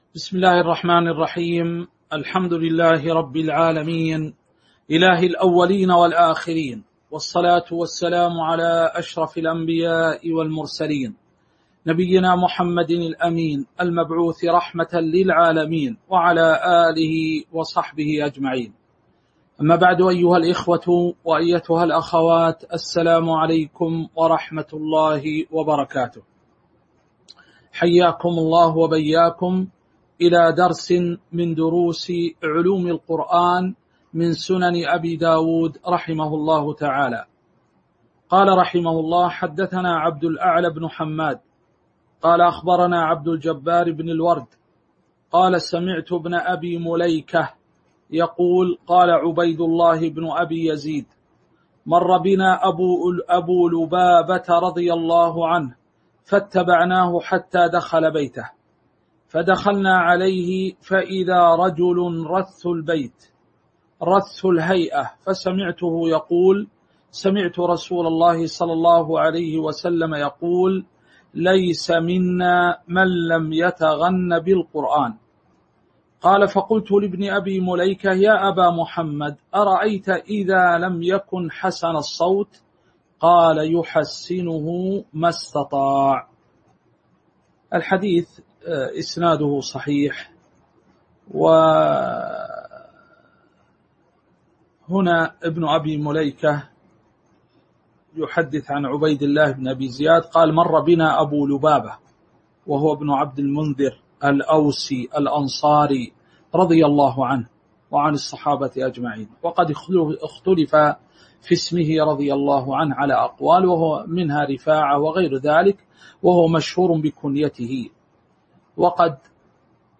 تاريخ النشر ٣ محرم ١٤٤٣ هـ المكان: المسجد النبوي الشيخ